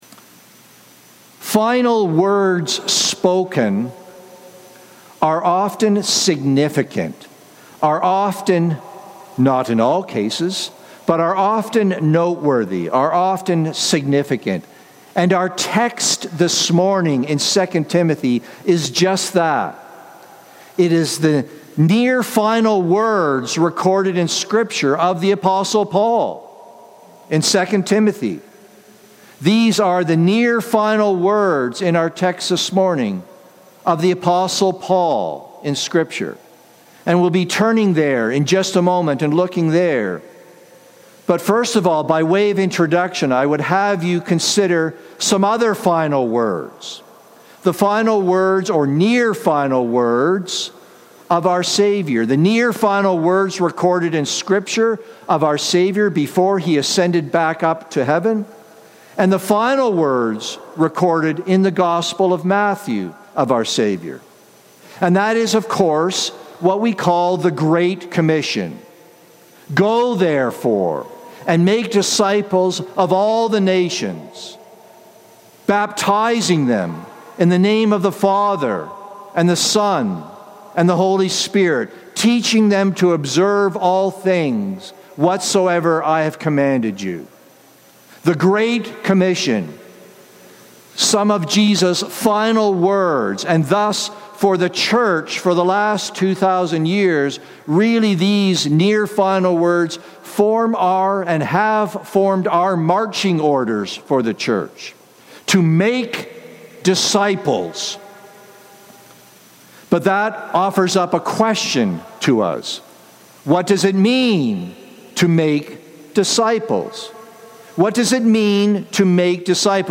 Sermons | Cranbrook Fellowship Baptist